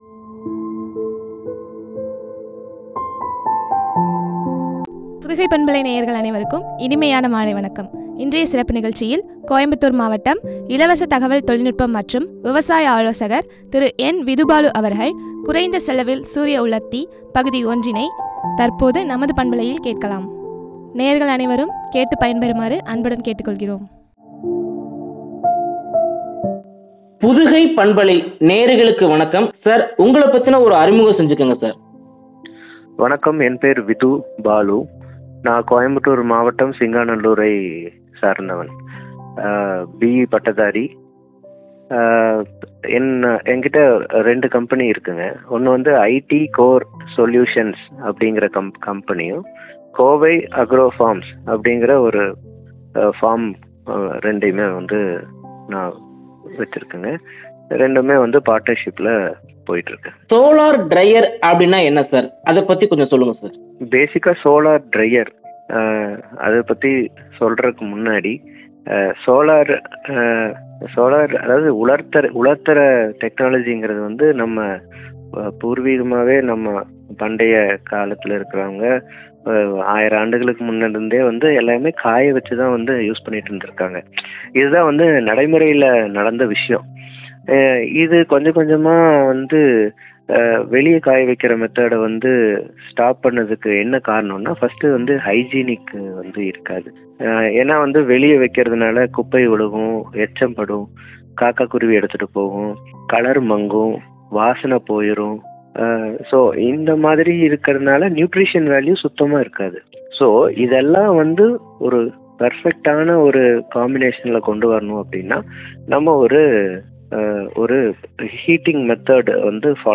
பகுதி -01” பற்றிய உரையாடல்